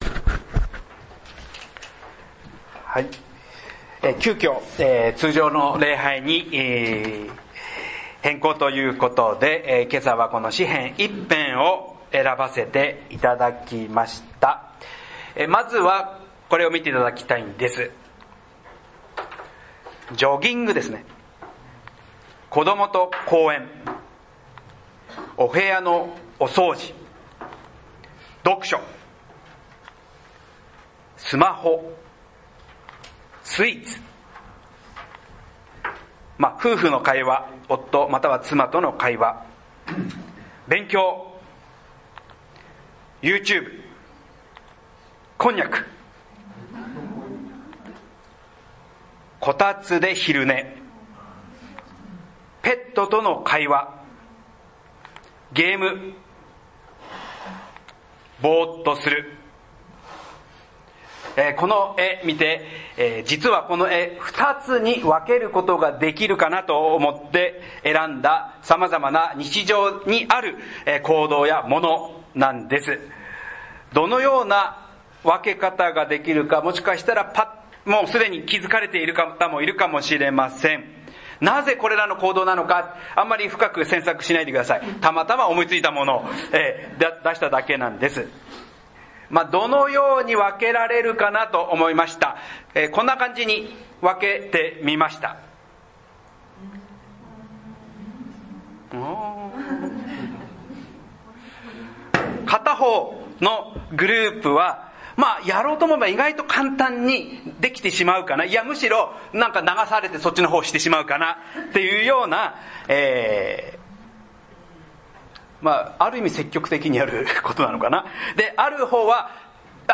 南浦和バプテスト教会礼拝メッセージ